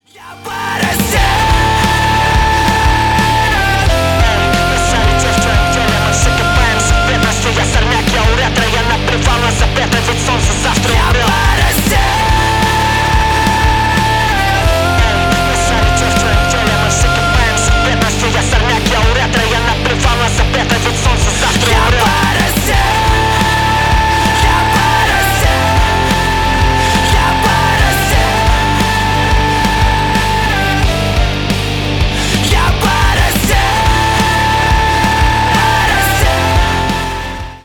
Рок Металл
громкие